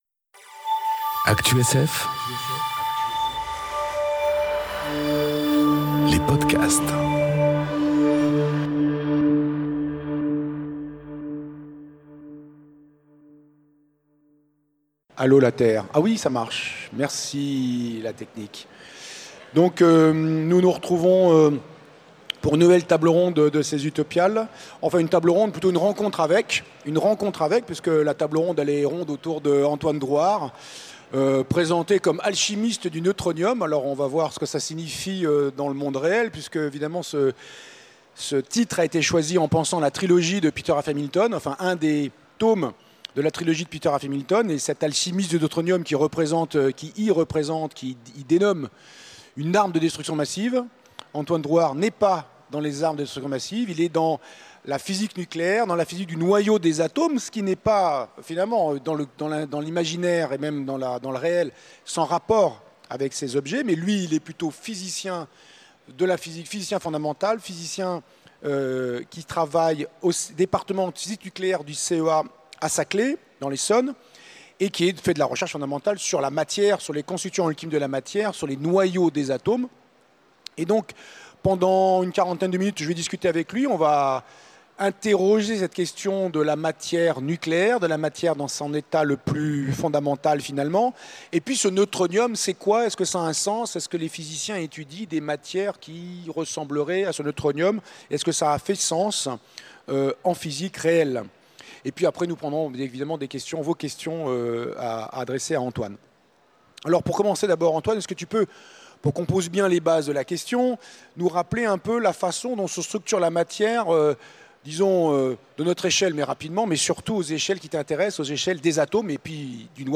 enregistrée aux Utopiales 2018
Conférence